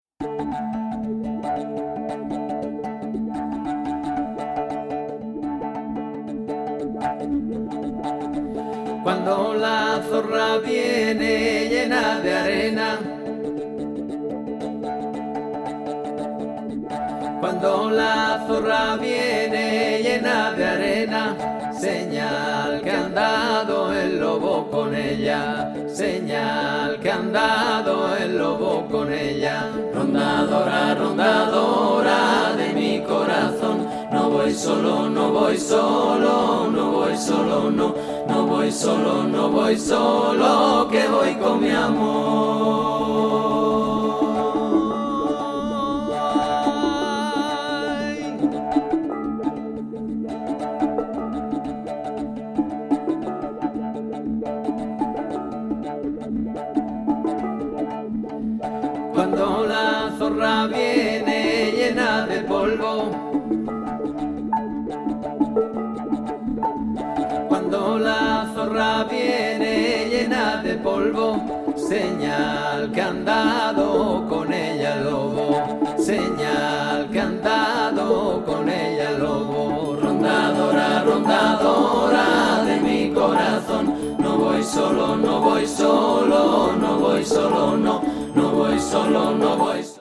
イベリアン・パーカッション・オーケストラの傑作セカンド！狂騒的かつスピリチュアル！
ヴァレンシアの子守歌やアンダルシアのプランテーション・ソングなどの伝承歌を、時に狂騒的に、時にスピリチュアルに展開！
歌（コーラス）と太鼓を中心に様々な楽器を加えながら素朴で荘厳で幻想的な世界を躍動感たっぷりに描いていますね！